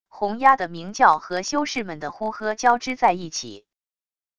红鸭的鸣叫和修士们的呼喝交织在一起wav音频